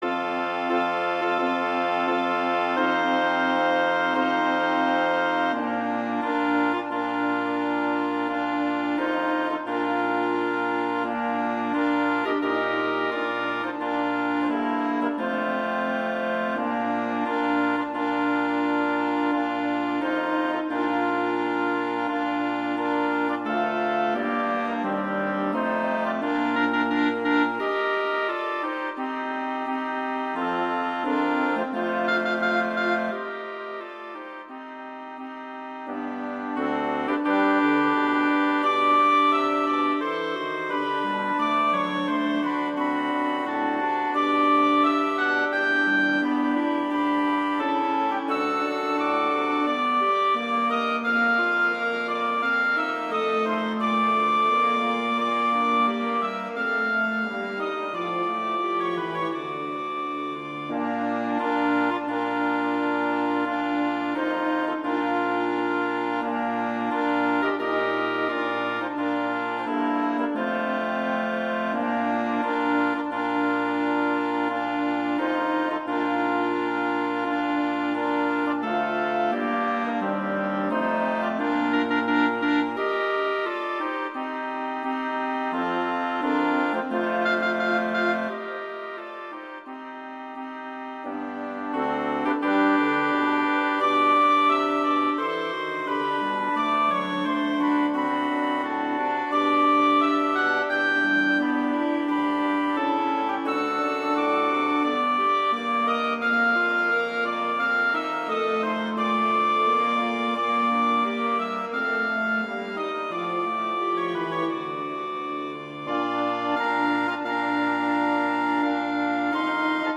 arrangements for wind quintet
wedding, traditional, classical, festival, love, french